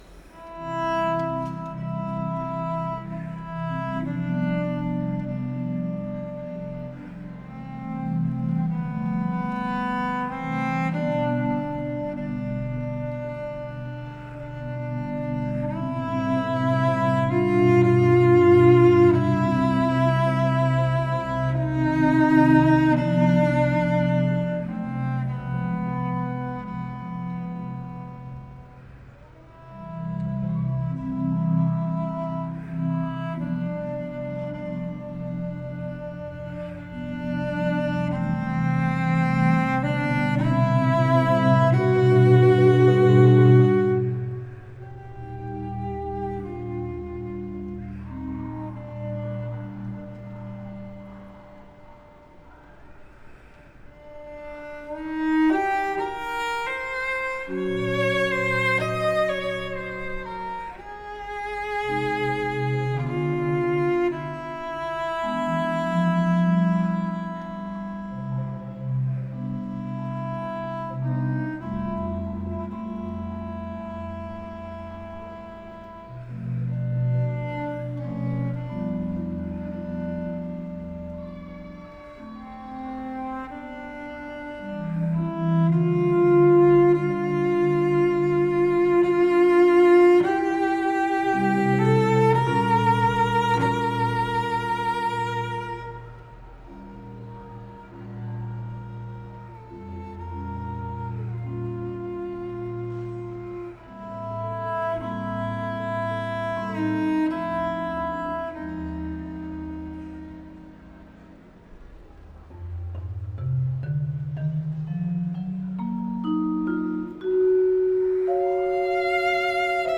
Genere: Classical.